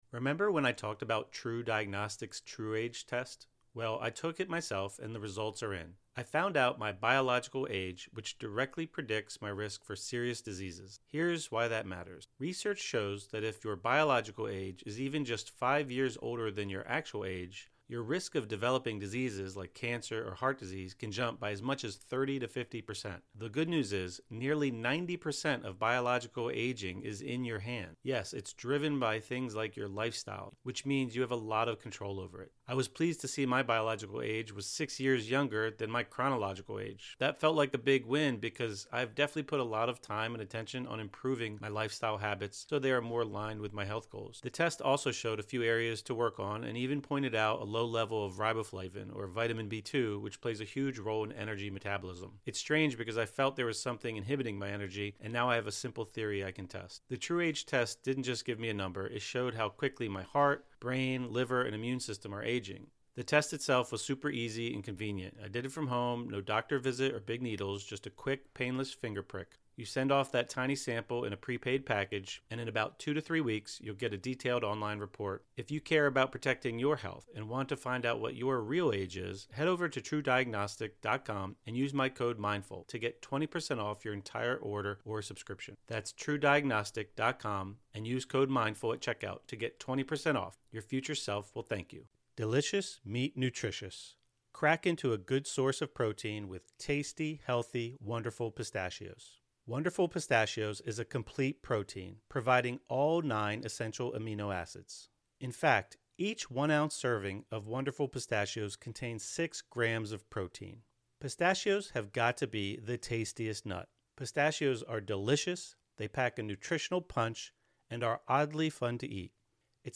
Morning Meditation | Using Gratitude to Transform a Challenging Time (; 23 Jun 2025) | Padverb